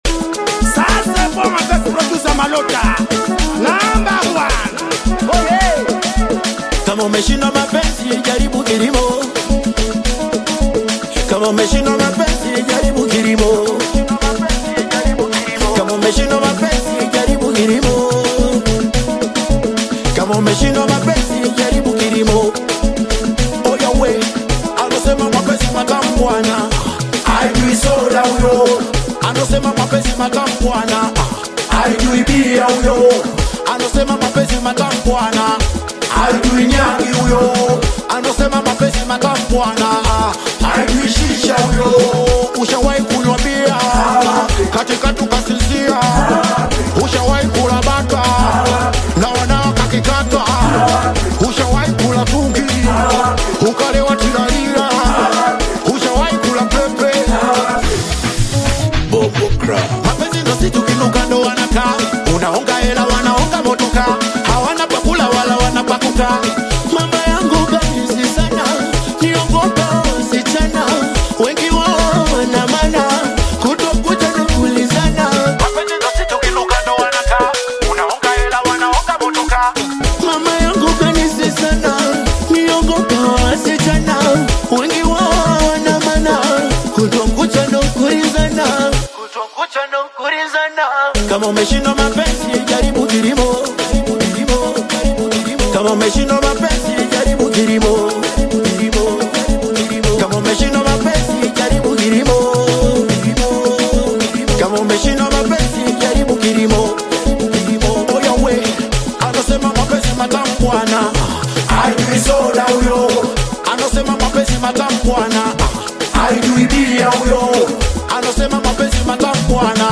rap single